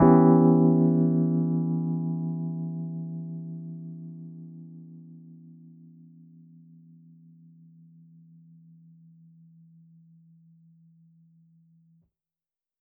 JK_ElPiano3_Chord-Em6.wav